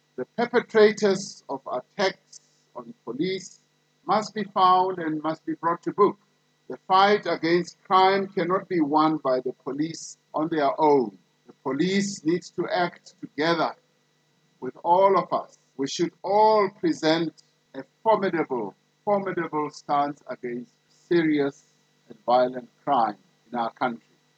During the annual South African Police Service Commemoration Day he said 34 officers have, between 1 April 2020 and 31 March 2021, been killed while on active duty.